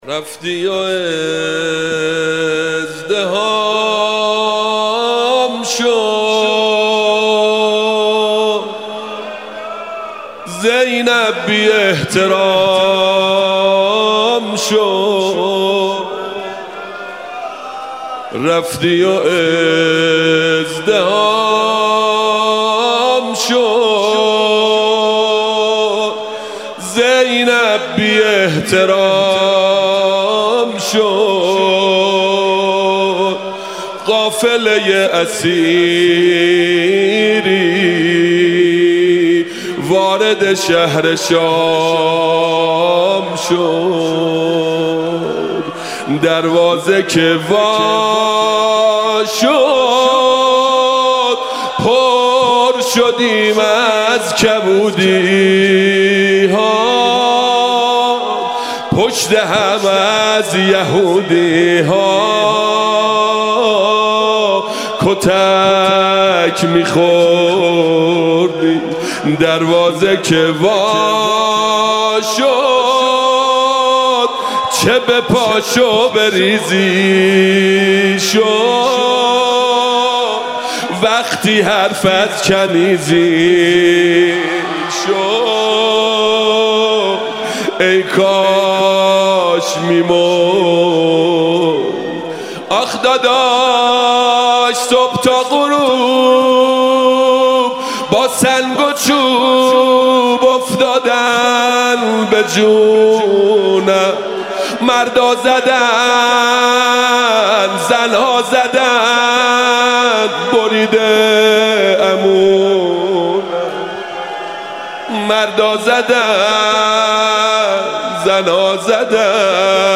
شب دوم مراسم مناجات شب های ماه مبارک رمضان مسجد ارک تهران